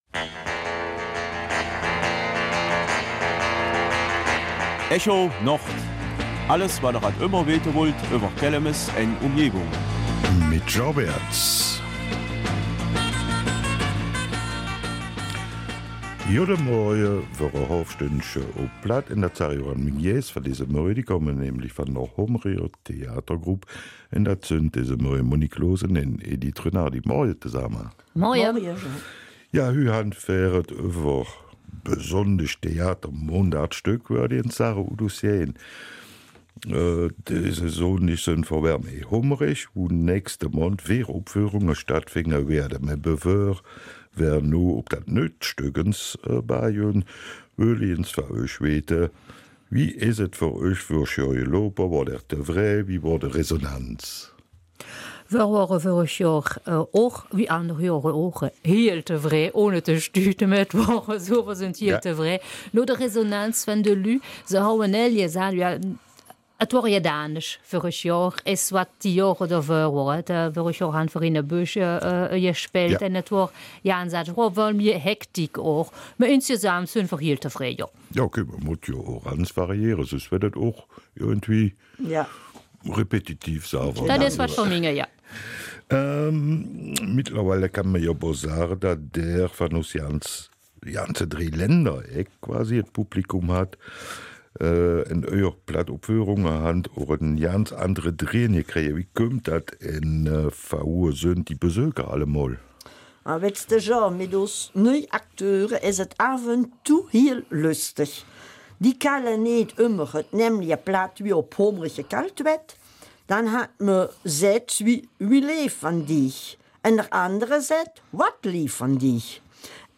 Kelmiser Mundart: Theater auf Platt in Hombourg